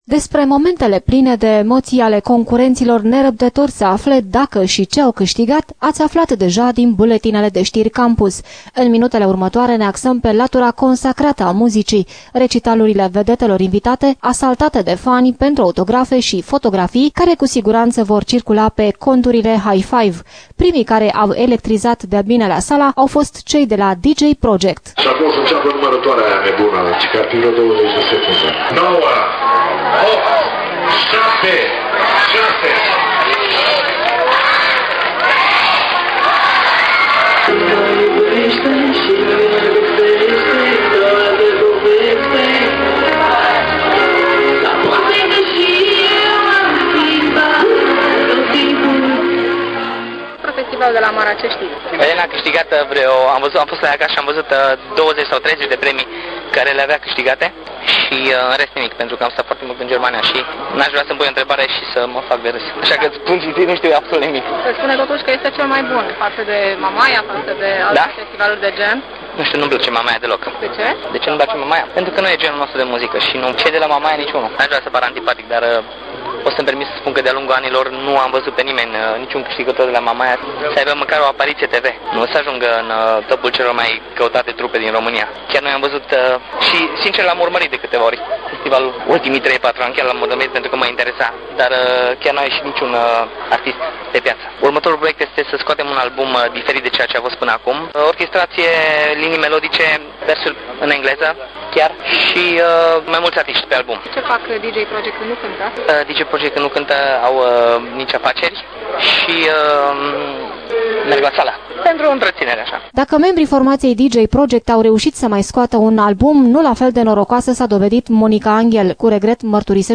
audio-festival-amara.mp3